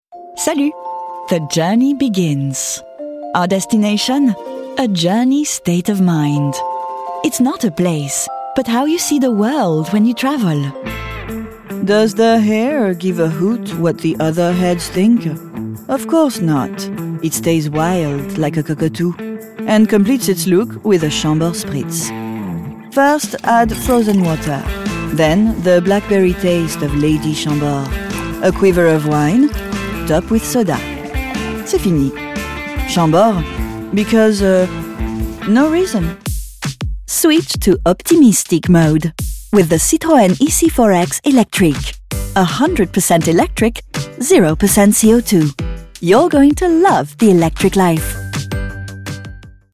Showreel
Female / 30s / English, French